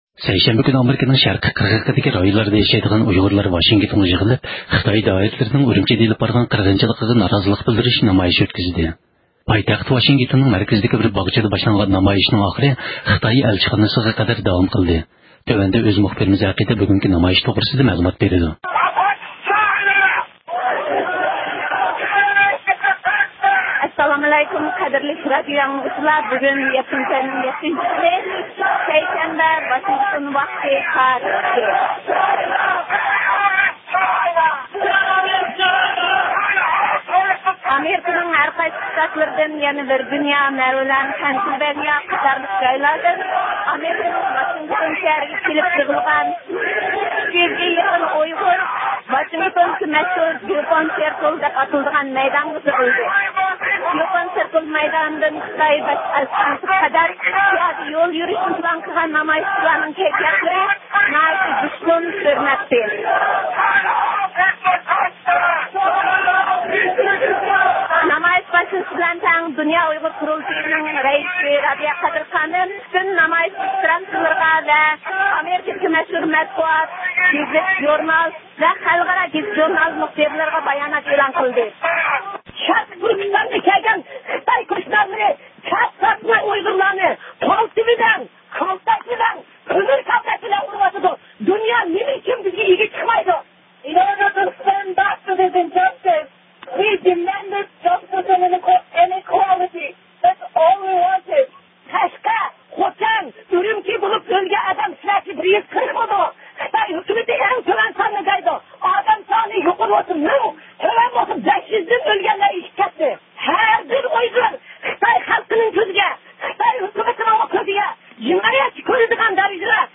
نامايىشقا 300 دىن ئارتۇق كىشى قاتناشقان بولۇپ، نامايىشتا ئۇيغۇر مىللىي ھەرىكىتىنىڭ رەھبىرى رابىيە قادىر خانىم نۇتۇق سۆزلىدى.
نامايىشنى ئامېرىكىنىڭ ئاساسلىق مەتبۇئاتلىرىدىن ۋاشىنگتون پوچتىسى گېزىتى، نيويورك ۋاقتى گېزىتى ۋە فرانسىيە بىرلەشمە ئاگېنتلىقىنى ئۆز ئىچىگە ئالغان خەلقئارا مەتبۇئاتلاردىن بولۇپ 10 دىن ئارتۇق گېزىت، تېلېۋىزىيە ۋە ژۇرناللارنىڭ مۇخبىرلىرى نەق مەيداندىن زىيارەت قىلدى.